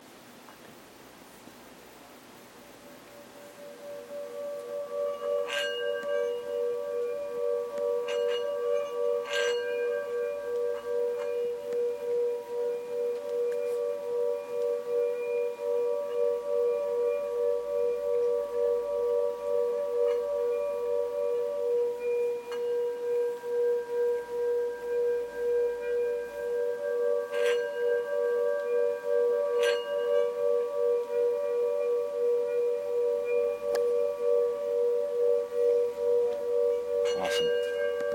Singing Bowls
Sounds heard: The playing of two singing bowls, some background noise.
Singing-Bowls.mp3